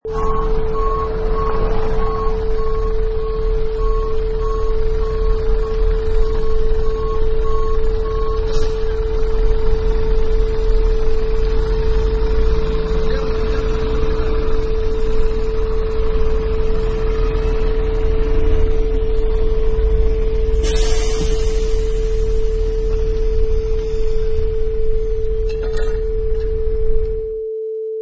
Sound: Dump Truck Backing up 2
Beep beep beep and engine sound of dump truck backing up
Product Info: 48k 24bit Stereo
Category: Vehicles / Trucks - Driving
Try preview above (pink tone added for copyright).
Dump_Truck_Backing_up_2.mp3